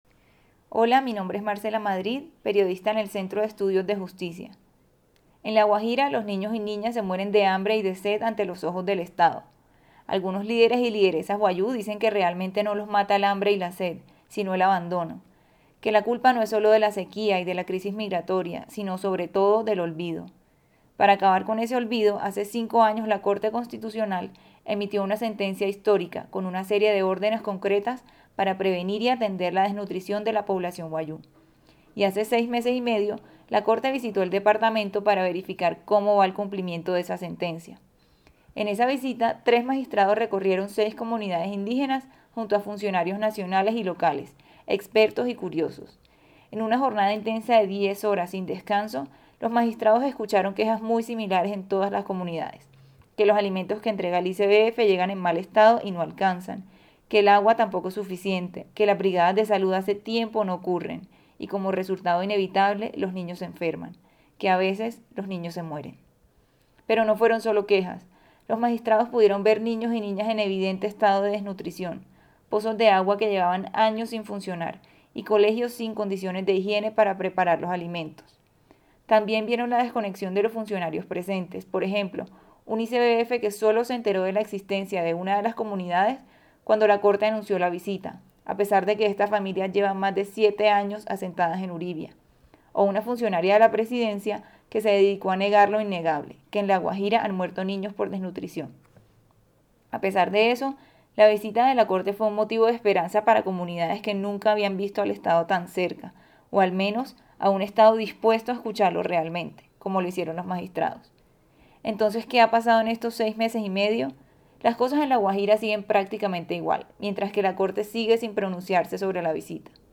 Escuche el audio que se emitió en RCN Cartagena: